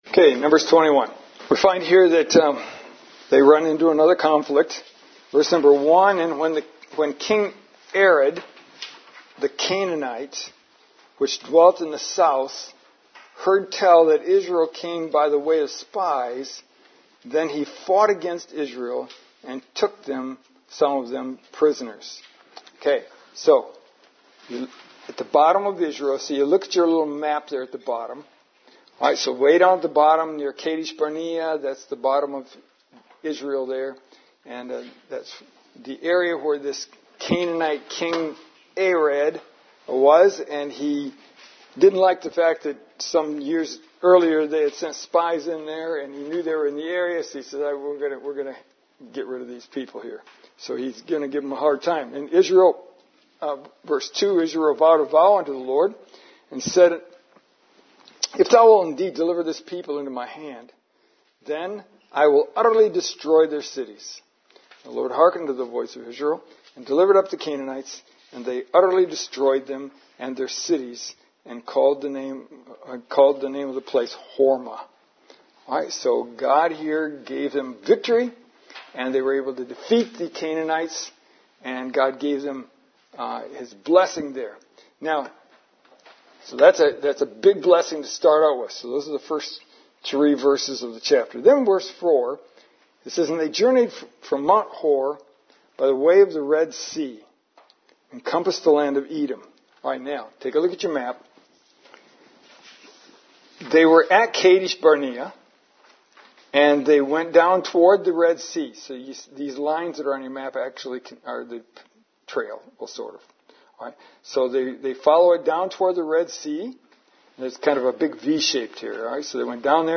NUMBERS 13 December 2023 Lesson: 14 Numbers 21 The Failure of Israel en route to Moab Once again, the main outline is from the Open Bible, slightly edited.